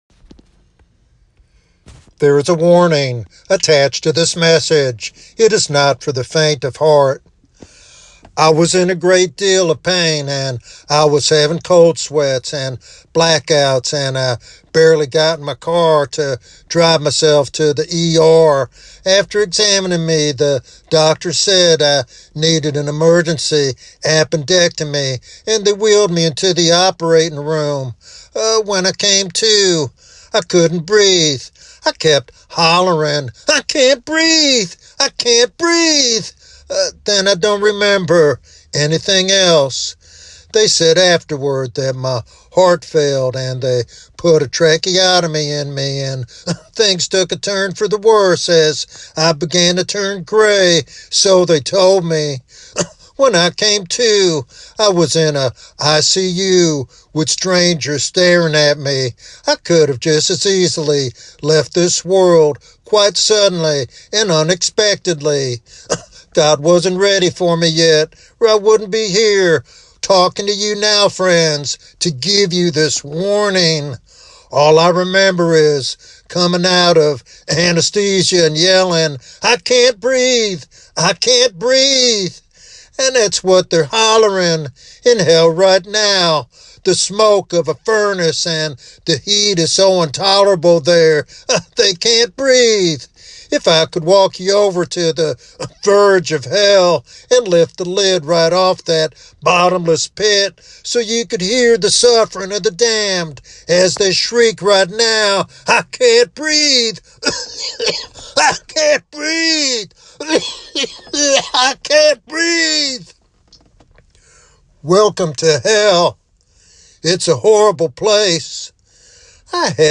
In this urgent and sobering sermon